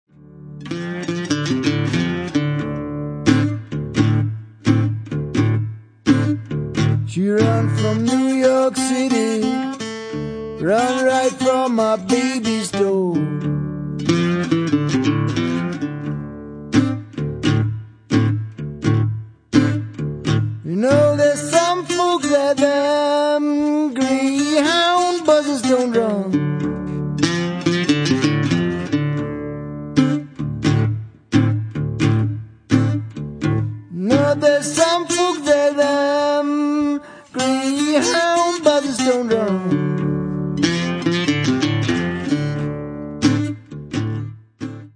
Blues-Klassiker